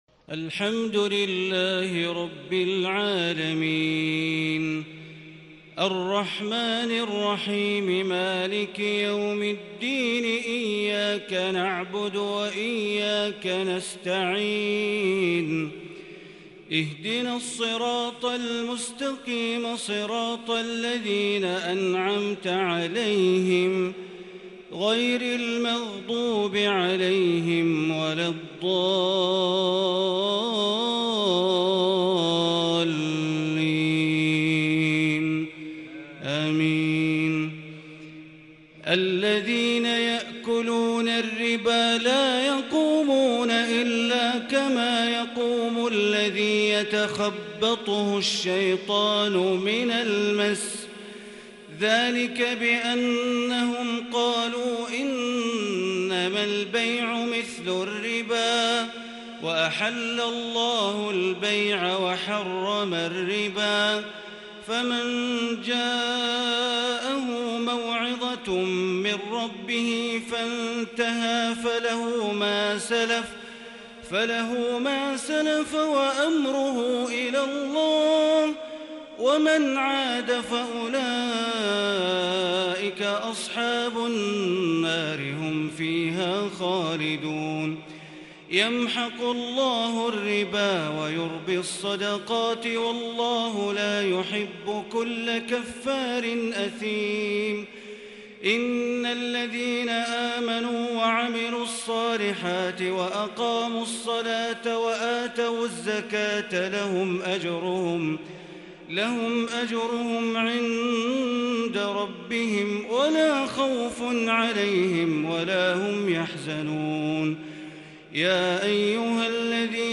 تراويح ليلة 4 رمضان 1442هـ من سورتي البقرة {275-286} و آل عمران {1-41} > تراويح ١٤٤٢ > التراويح - تلاوات بندر بليلة